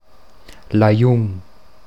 Ladinisch-mundartliche Form
[laˈjuŋ]